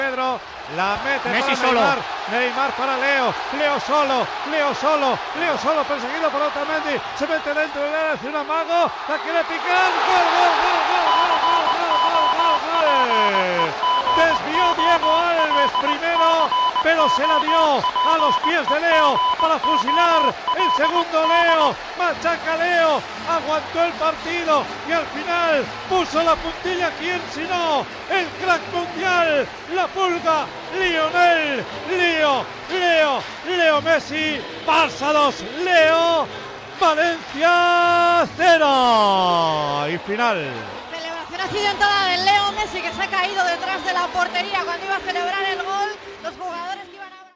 Transmissió del partit de la lliga masculina de futbol entre el Futbol Club Barcelona i el València Club de Futbol.
Narració del gol de Leo Messi i final del partit .
Esportiu